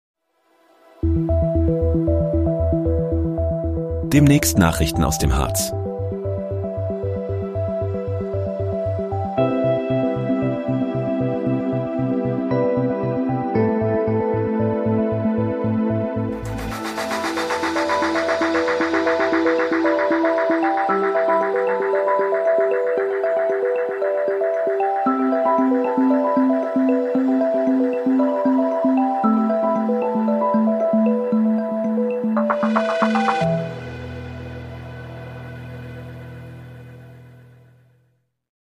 Hallo Harz: Trailer, erstellt mit KI-Unterstützung